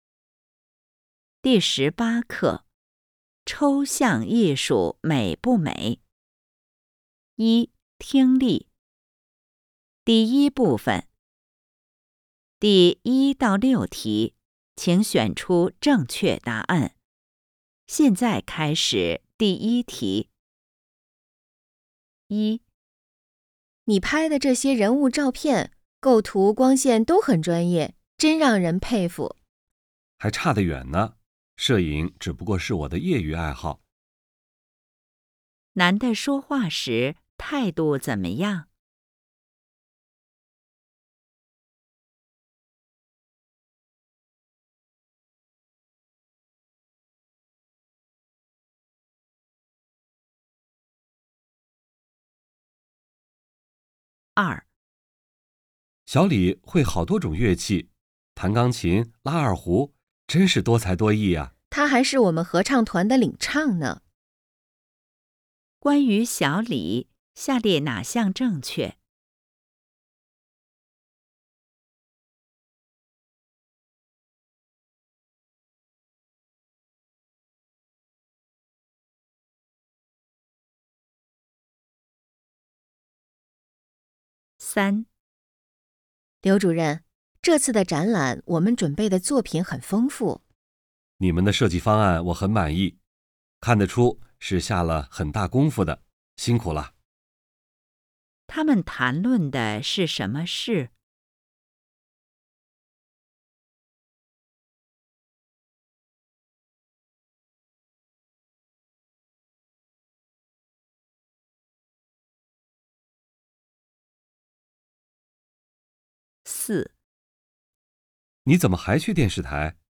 一、听力